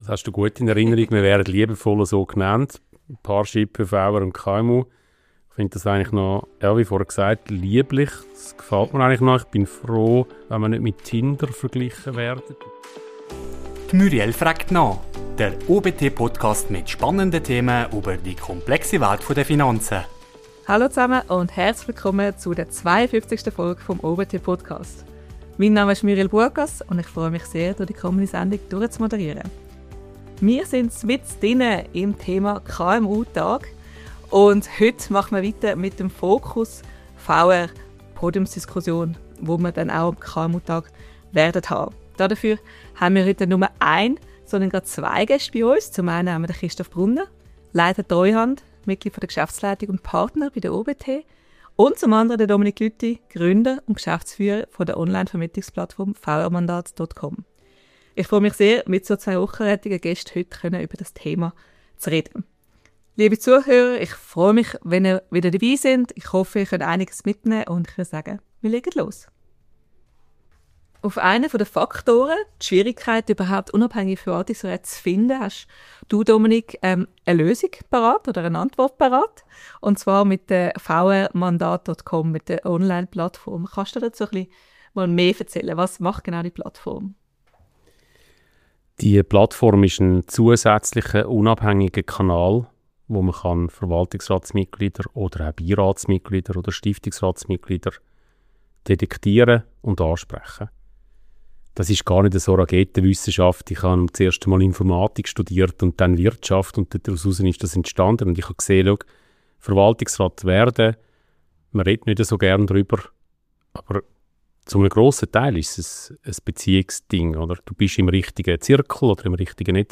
Dies ist Teil 2 der Podiumsdiskussion vom KMU-Tag Schweiz – die Fortsetzung und Abrundung von Episode #51.